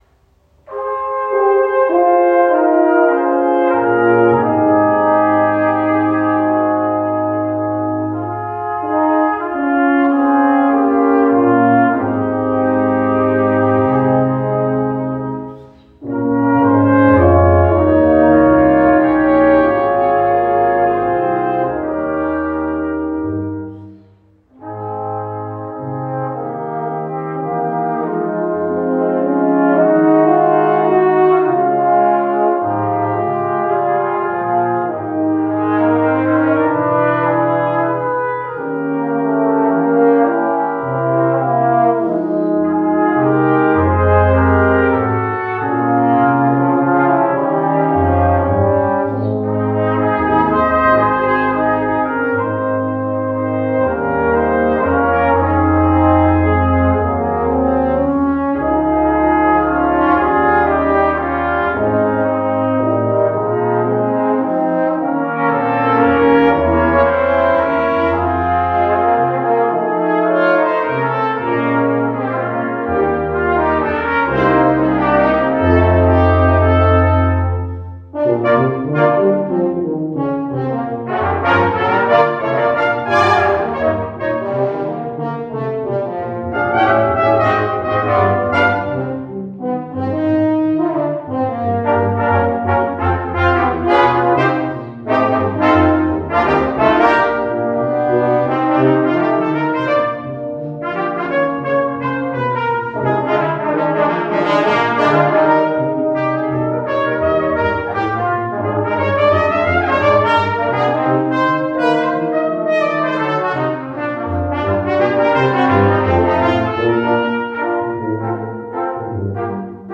Ein echter Weihnachtsklassiker im Jazz-Style